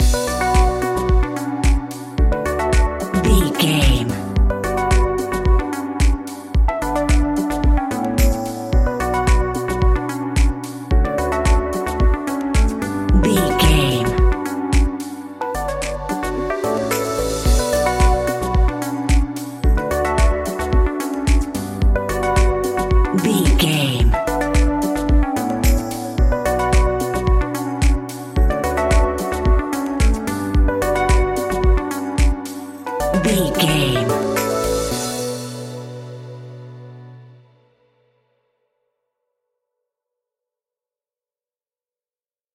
Aeolian/Minor
groovy
dreamy
tranquil
smooth
futuristic
drum machine
synthesiser
house
electro
synth pop
synth leads
synth bass